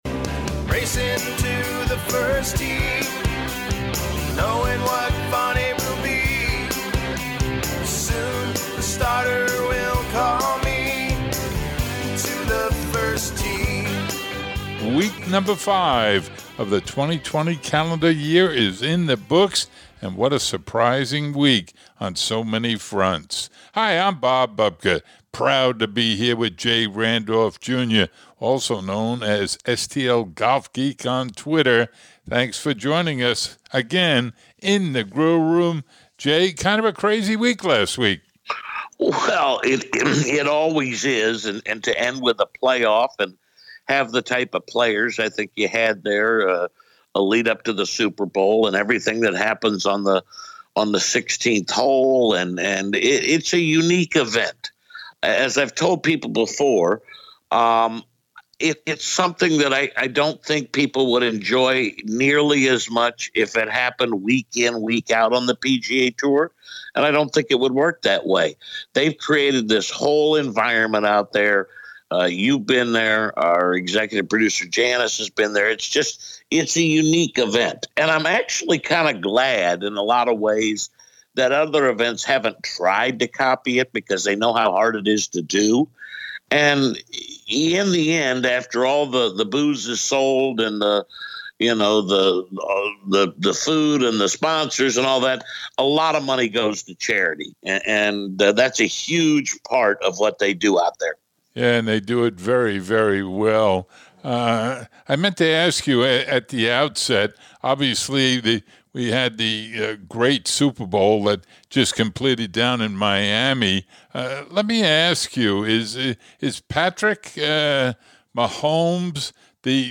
Feature Interviews: Precision Pro Golf, Pitc...